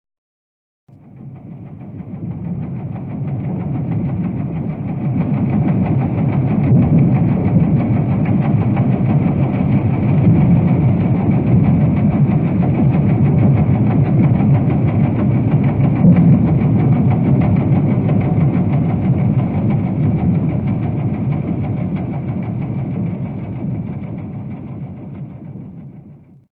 En écho à ces réflexions, les sons à découvrir proposent une incursion dans la vie aquatique, entre la nature sauvage et les activités humaines.
propulsion.mp3